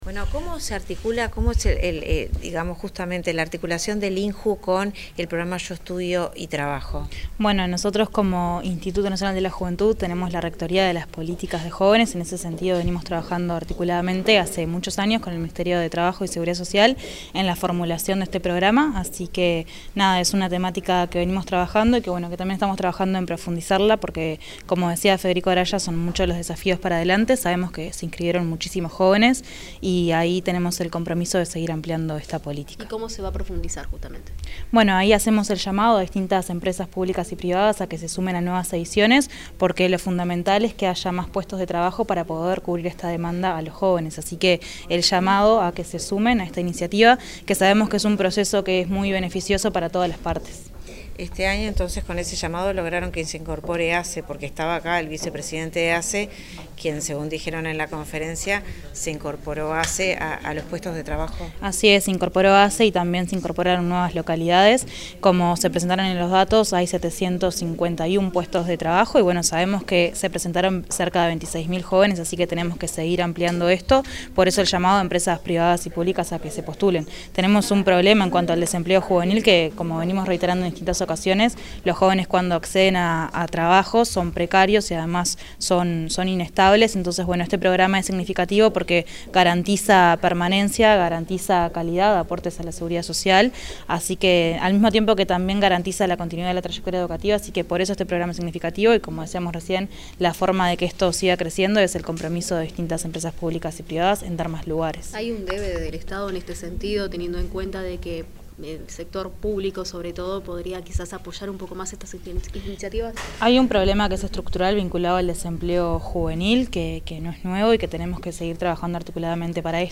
Declaraciones de la directora del INJU, Eugenia Godoy
Declaraciones de la directora del INJU, Eugenia Godoy 29/10/2025 Compartir Facebook X Copiar enlace WhatsApp LinkedIn La directora del Instituto Nacional de la Juventud (INJU), Eugenia Godoy, brindó declaraciones a los medios periodísticos tras el sorteo de la 14.ª edición del programa Yo Estudio y Trabajo.